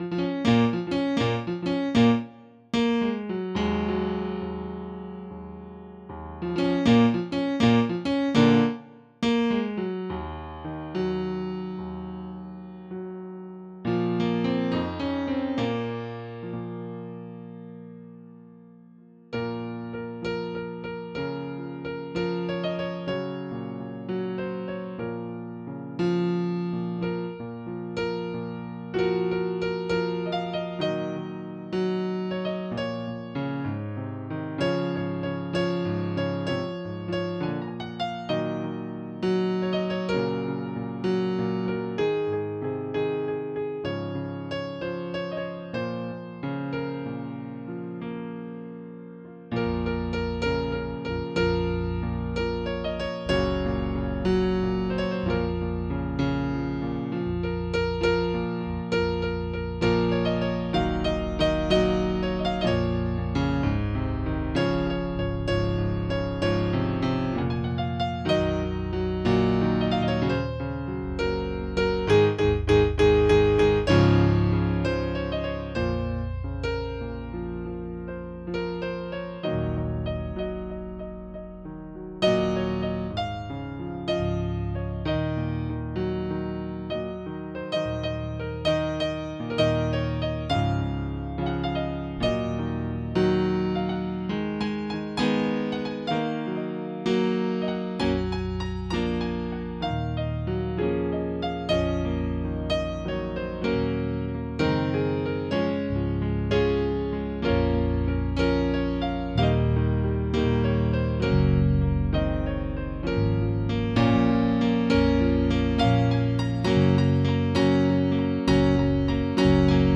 ביצוע פסנתר